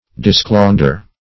Search Result for " disclaunder" : The Collaborative International Dictionary of English v.0.48: Disclaunder \Dis*claun"der\, v. t. [From OE. disclaundre, n., for sclandre, esclandre, OF. esclandre.